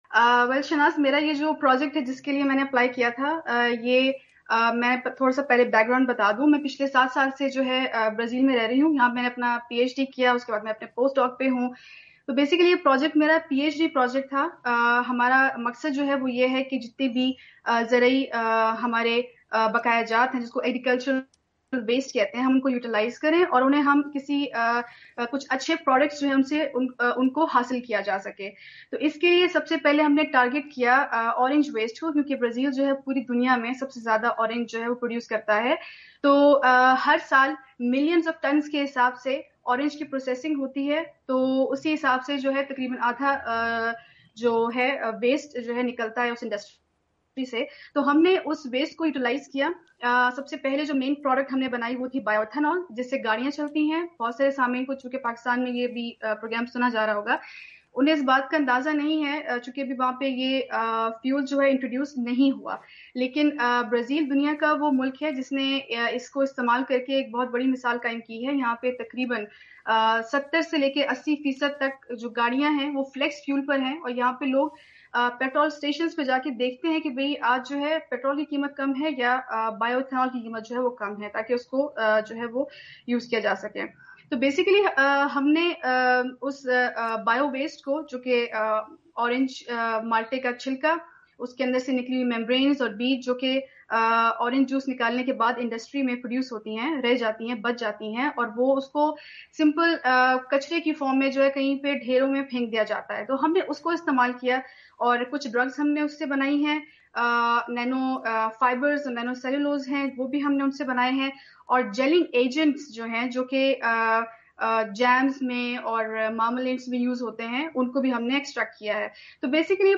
خصوصی انٹرویو